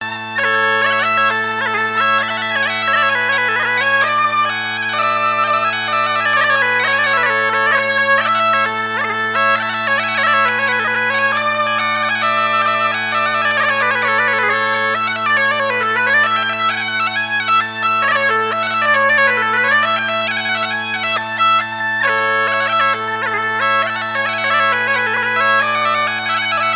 gestuel : à marcher
circonstance : fiançaille, noce
Pièce musicale éditée